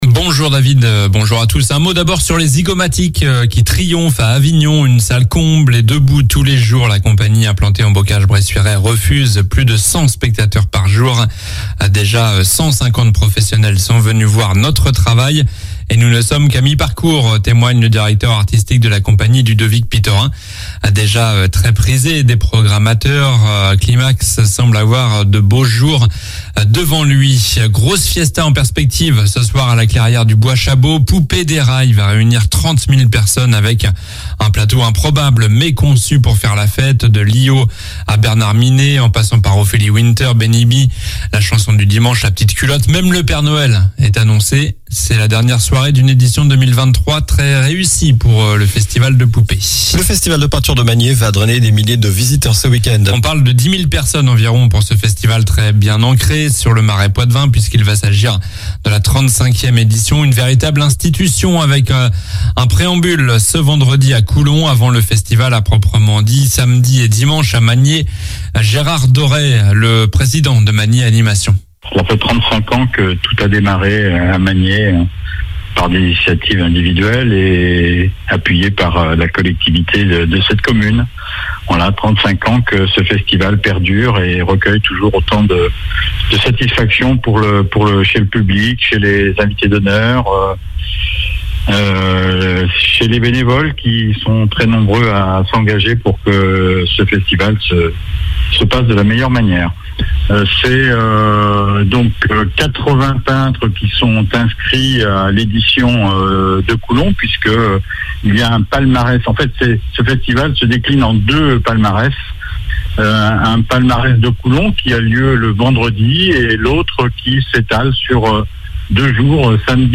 Journal du vendredi 21 juillet (midi)